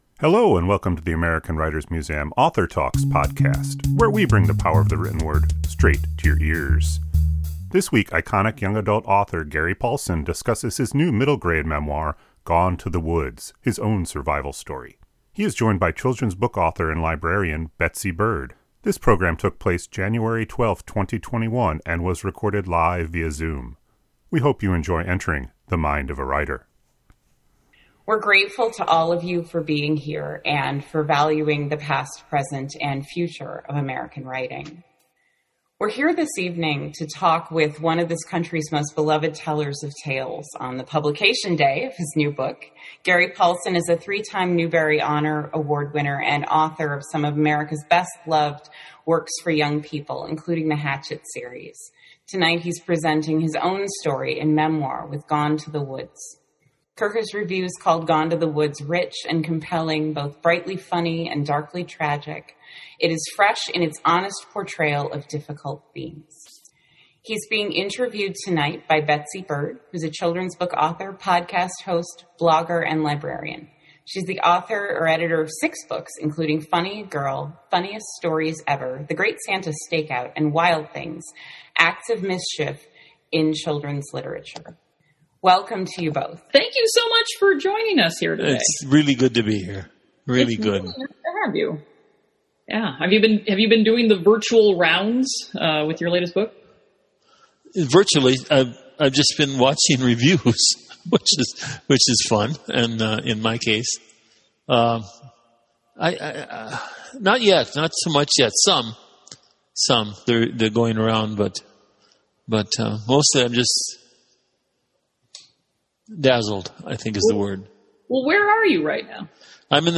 This program took place January 12th, 2021 and was recorded live via Zoom.